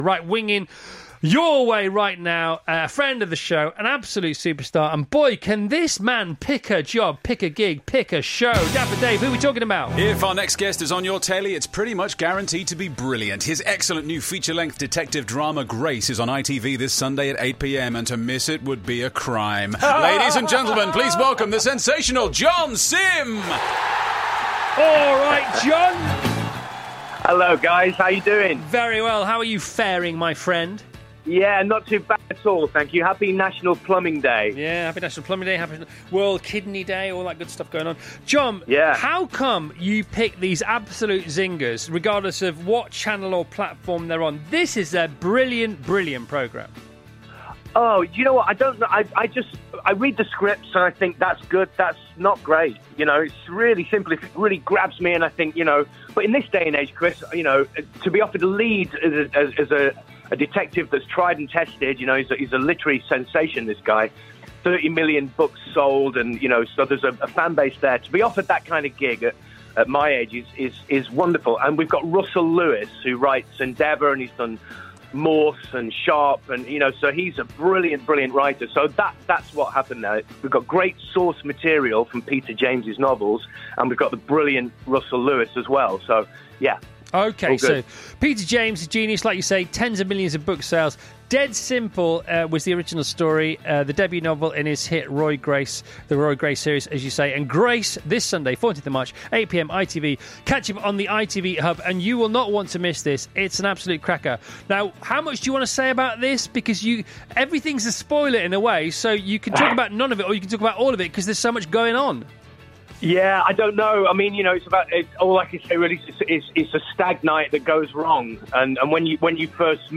John Simm chats about new Drama ‘Grace’ on the Chris Evans Breakfast Show
Play Radio Interview (00:12:17):